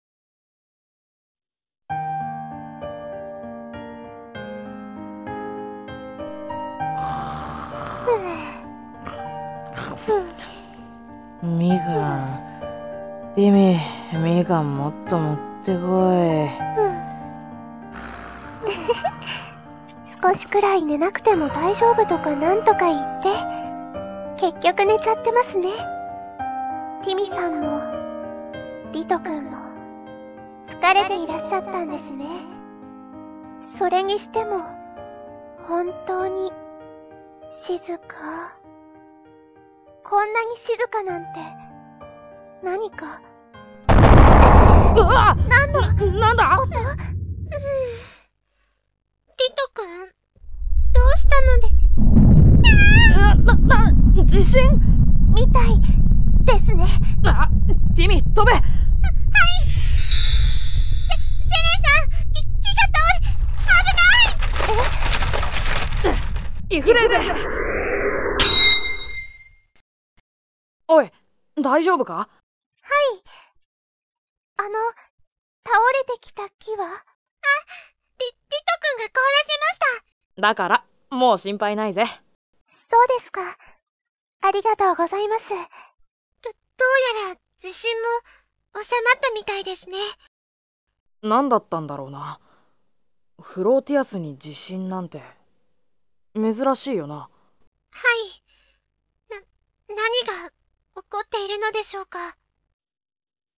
Flotias-2つの世界- *ドラマ「セレン編」*